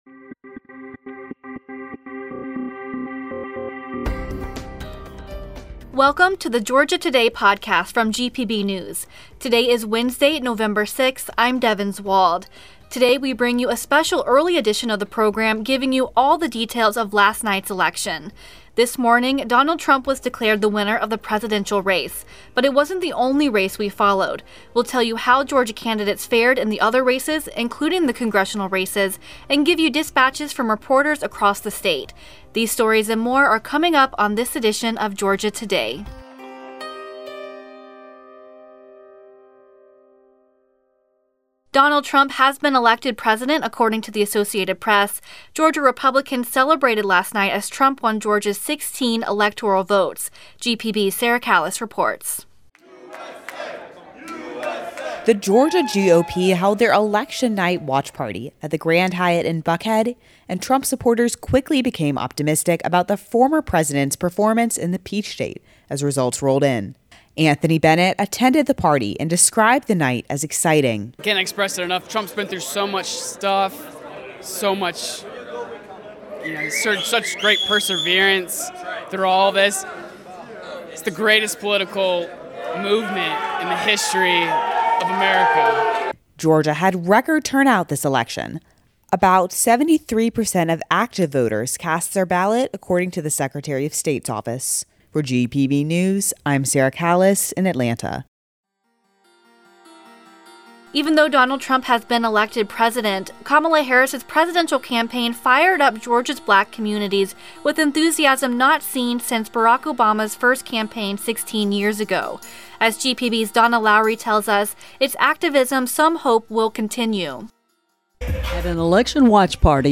But it wasn't the only race we followed. We'll tell you how Georgia candidates fared in the other races, including the Congressional race, and give you dispatches from reporters across the state.…